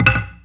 stake_drop.WAV